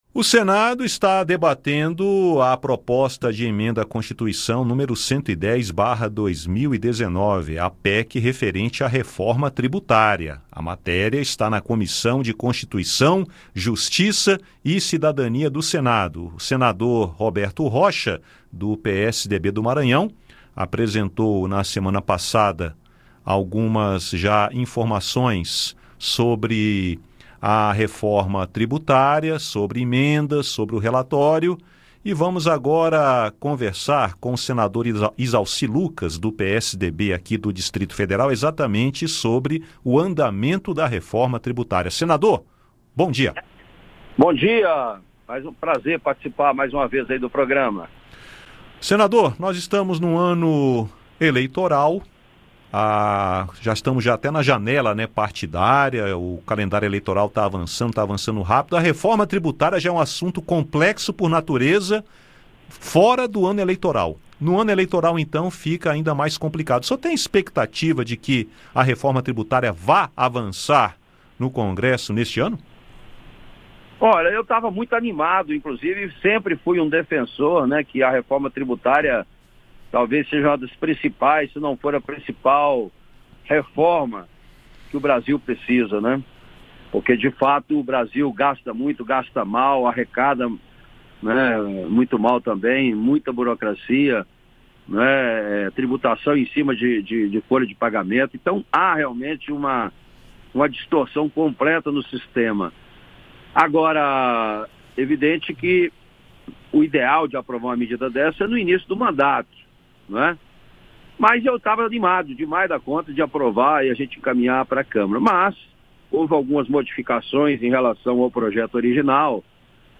O Conexão Senado convidou o senador Izalci Lucas (PSDB-DF) para falar sobre as principais mudanças do novo relatório. Para Izalci, essas mudanças têm impacto direto sobre a educação, a ciência e a tecnologia e será difícil aprovar o texto esta semana. O senador acredita ser necessário um plano de metas para acrescentar tributações especiais para a educação e a ciência ao novo texto.